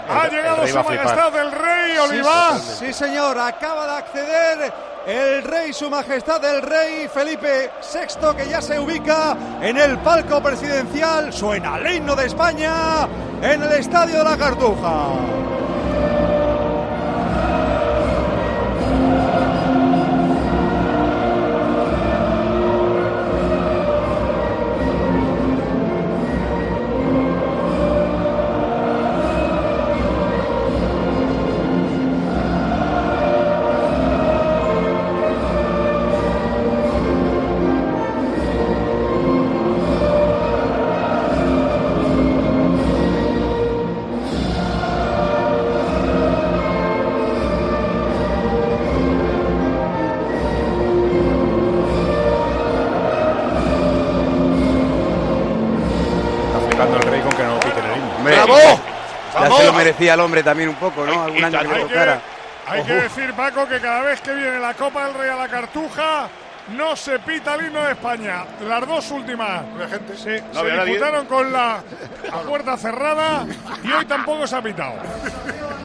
Sin pitos al himno de España en la final de Copa del Rey entre Betis y Valencia
Ambas aficionados fueron respetuosas cuando onó por la megafonía el himno de España en los prolegómenos de la final de Copa del Rey entre Betis y Valencia en La Cartuja.
El himno de España volvió a sonar con fuerza en la final de la Copa del Rey entre el Betis y el Valencia en La Cartuja.